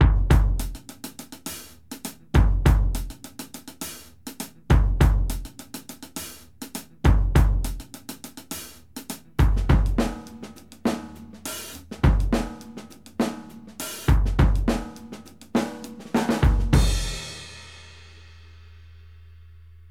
Характерен незаглушенной мембраной и долгим релизом. Примерно вот такой, как в файле.